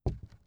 ES_Walk Wood Creaks 7.wav